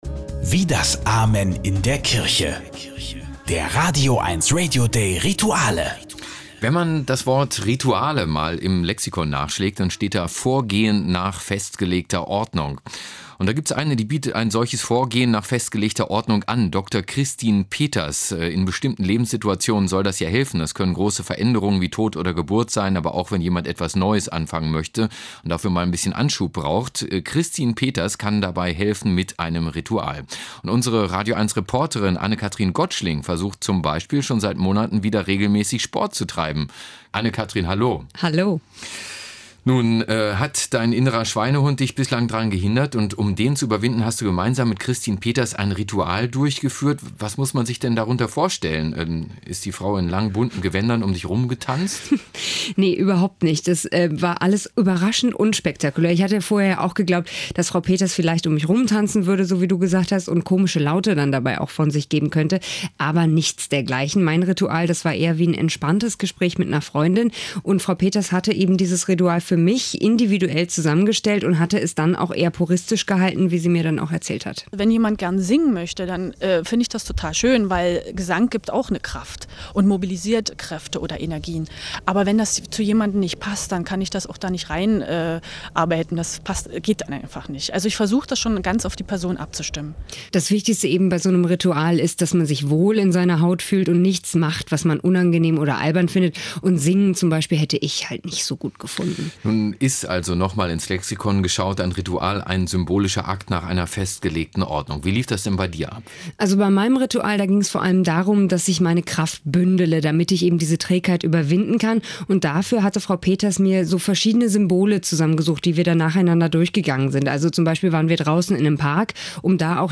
Beitrag im Radio Eins vom 09. April 2007